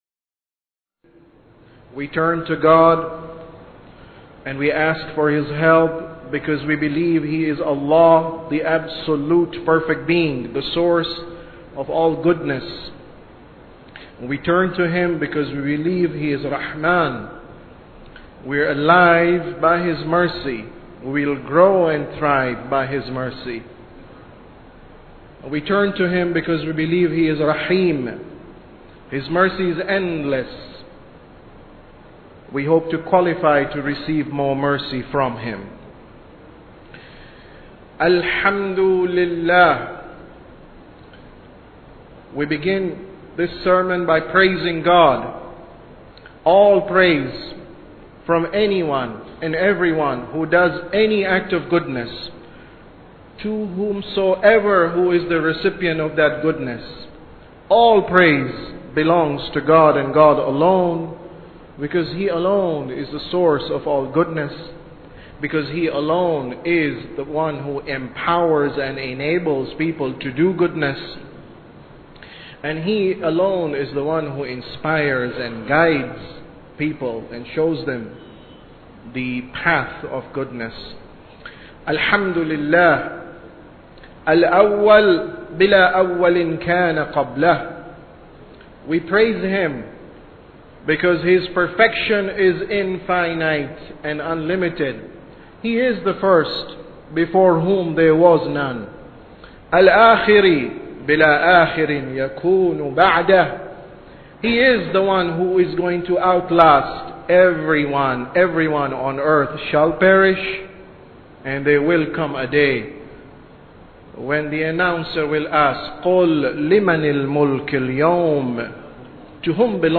Sermon About Tawheed 3